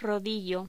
Locución: Rodillo
Sonidos: Voz humana